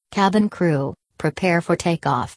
takeoff.wav